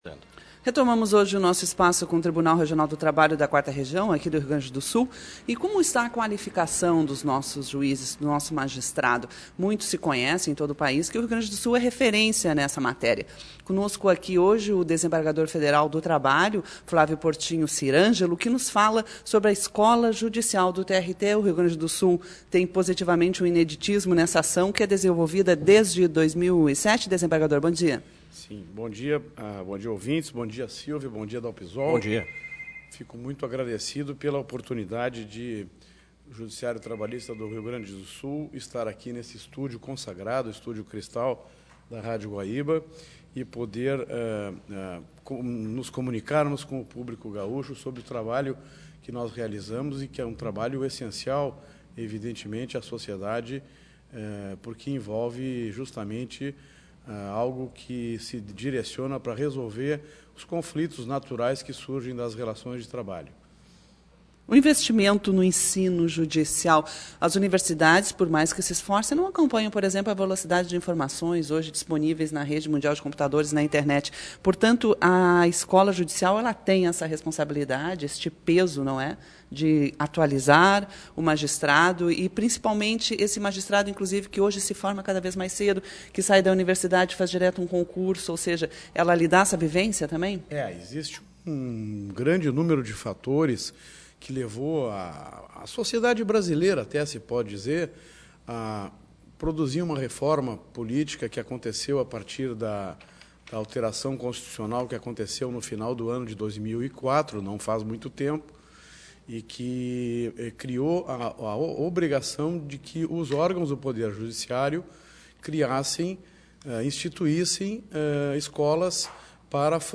Guaíba AM: Desembargador Sirangelo concede entrevista